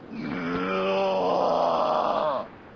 mgroan3.ogg